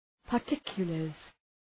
Προφορά
{pər’tıkjələrz}